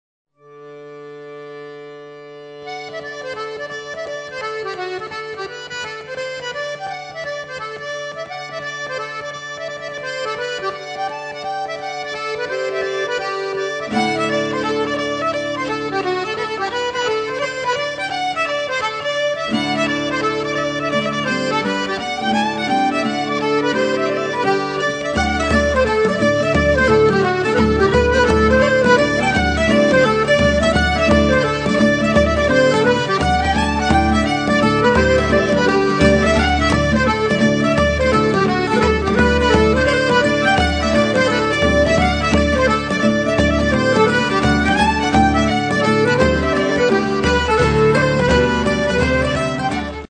česká world music v keltském stylu.
housle, kytary, mandolína, zpěv
bodhrán, bicí, perkuse
akordeon, kytary, mandolína, zpěv
flétna, píšťaly, zpěv